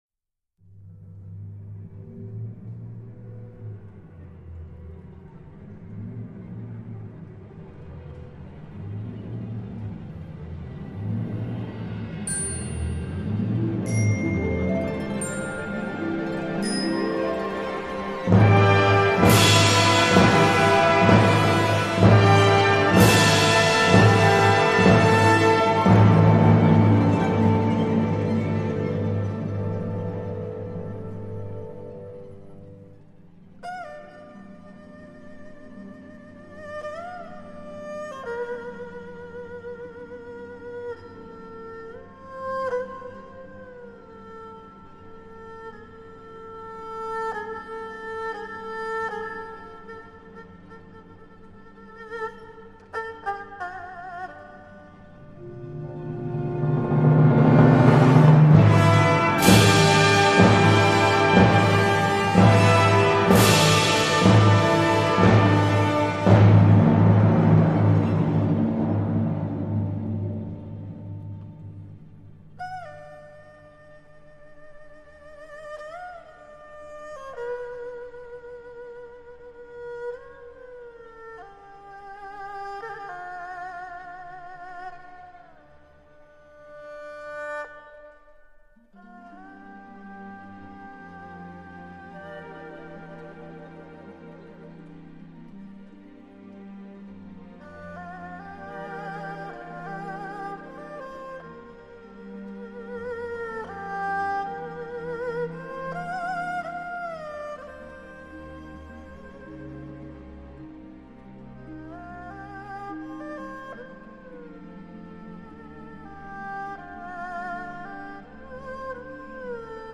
音地北京車道溝軍樂團錄音棚